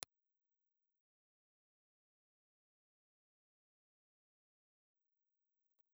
Ribbon
Impulse Response file of National WM-702 in filter position 10
National_WM702_IR_HPF10.wav